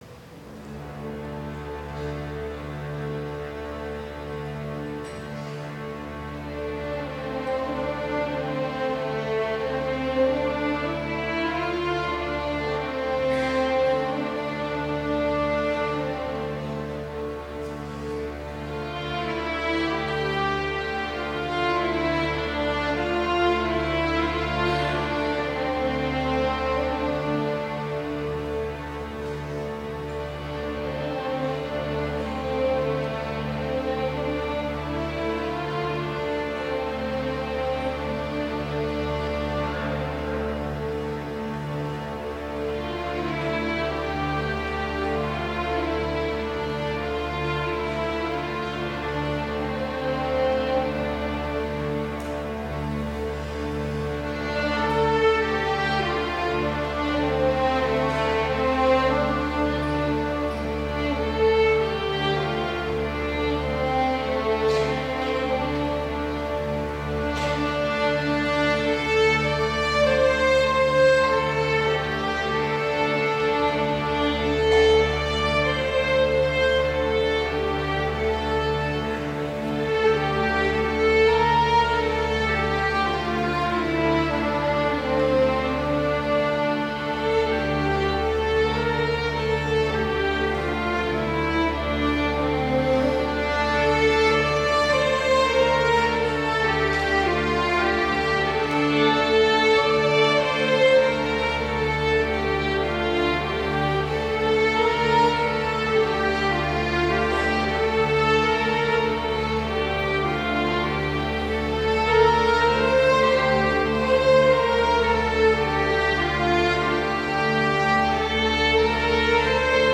Instrumentation: String Quartet
Sacred Music
Chamber Music
Violin , Viola , Cello , Contrabass